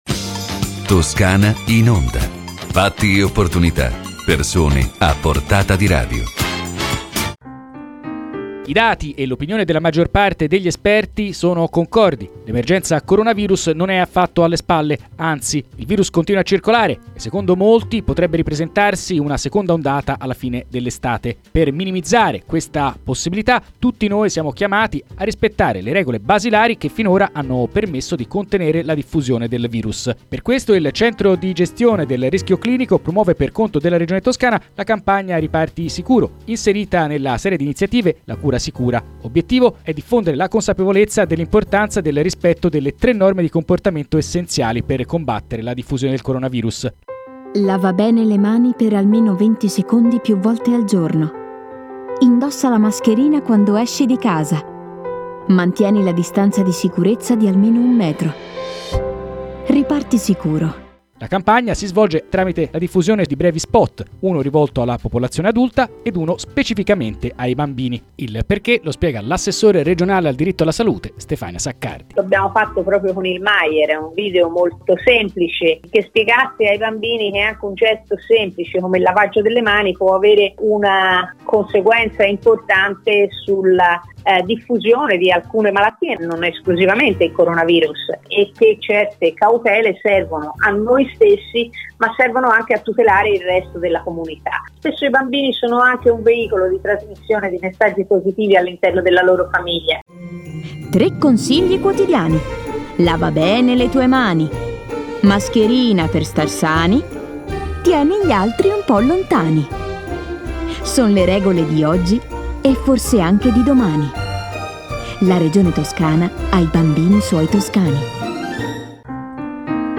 Le interviste radiofoniche
• Stefania Saccardi,  Assessore alla Salute della Regione Toscana